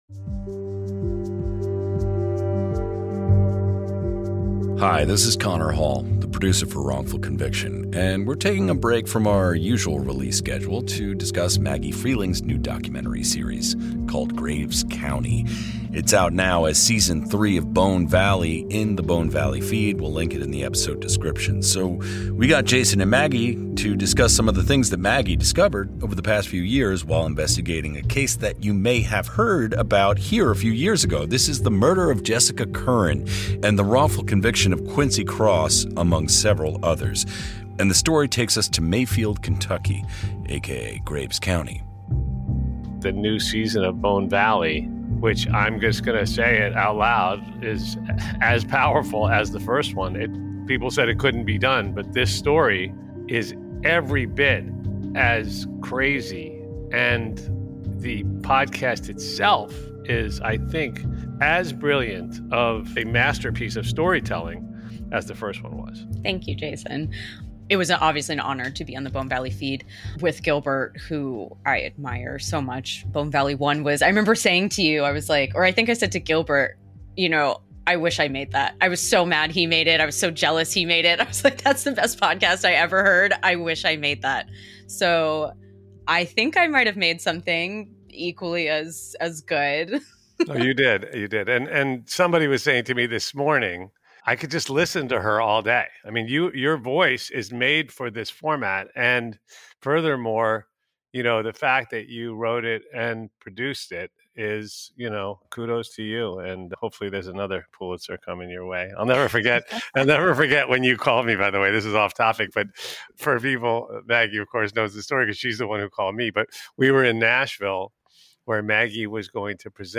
Jason Flom sits down for an interview